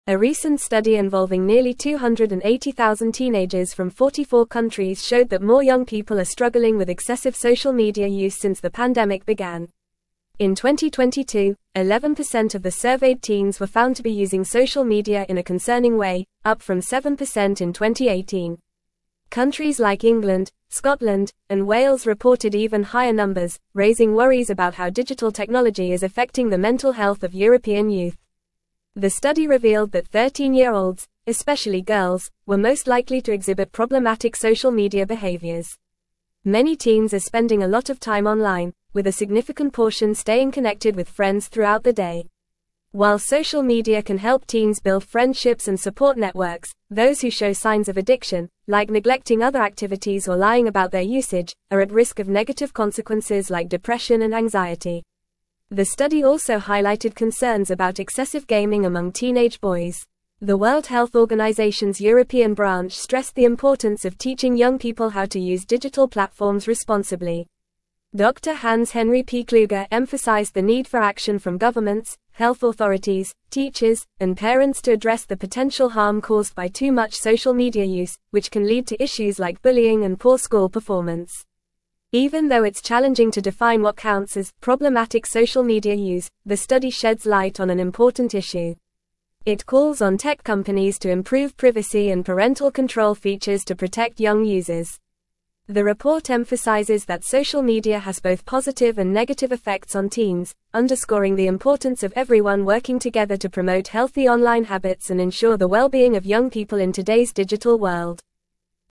Fast
English-Newsroom-Upper-Intermediate-FAST-Reading-Concerning-Increase-in-Teenage-Social-Media-Use-Detected.mp3